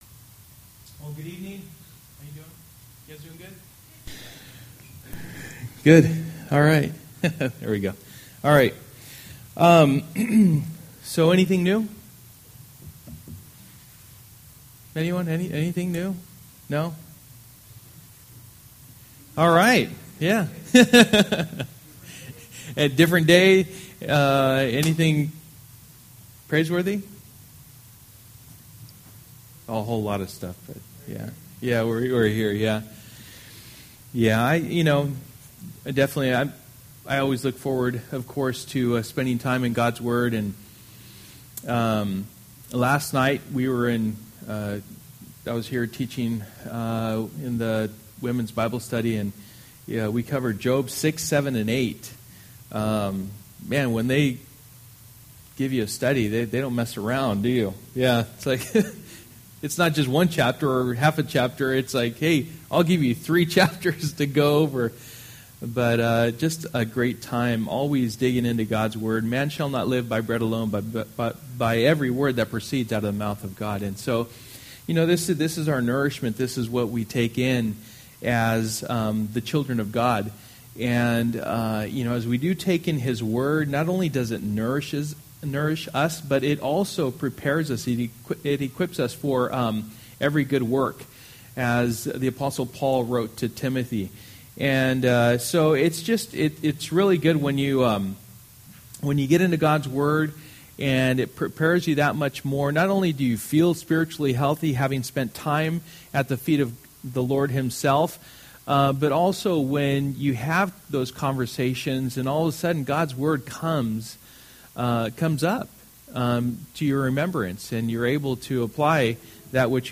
Through the Bible Passage: Leviticus 8:1-36 Service: Wednesday Night %todo_render% « Excelling Generosity